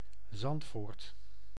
Zandvoort (Dutch pronunciation: [ˈzɑntfoːrt]